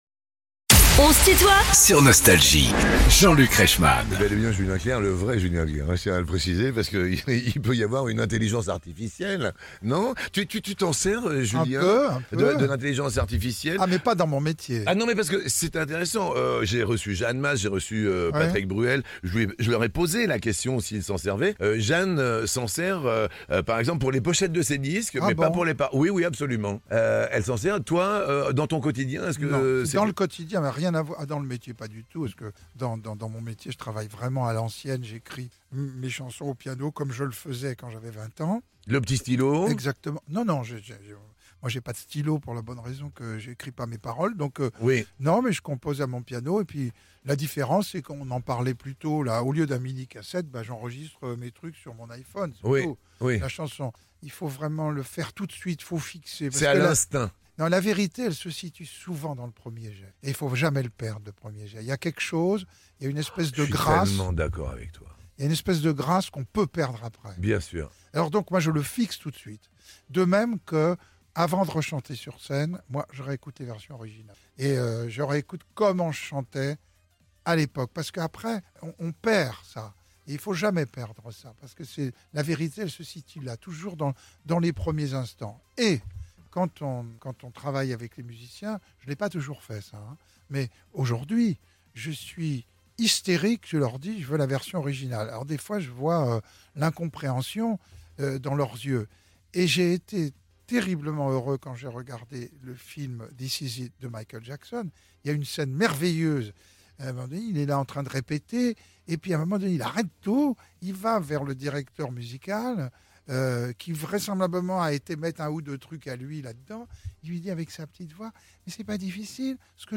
Julien Clerc est l'invité de "On se tutoie ?..." avec Jean-Luc Reichmann (Partie 1) ~ Les interviews Podcast